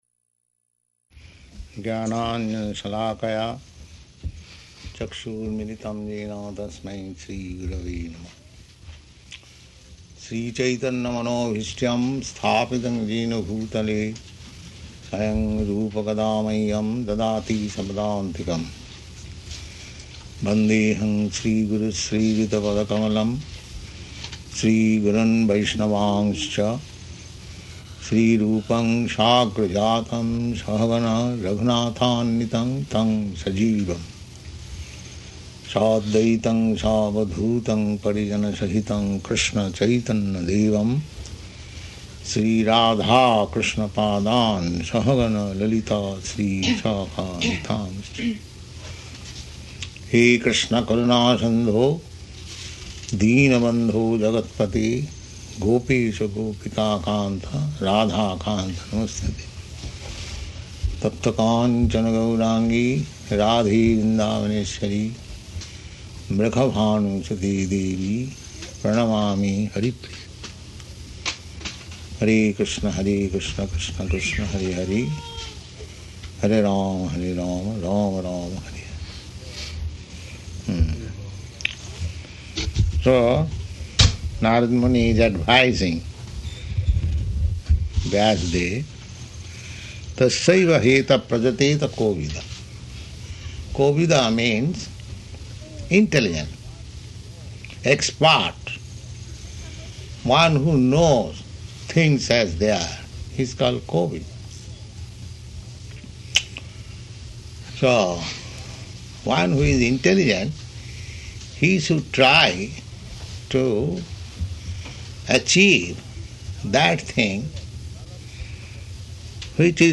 Location: New Vrindavan
[chants maṅgalācaraṇa ]